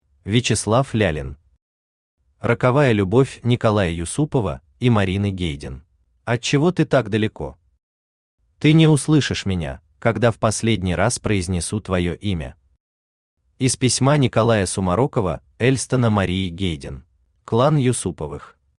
Аудиокнига Роковая любовь Николая Юсупова и Марины Гейден | Библиотека аудиокниг
Aудиокнига Роковая любовь Николая Юсупова и Марины Гейден Автор Вячеслав Егорович Лялин Читает аудиокнигу Авточтец ЛитРес.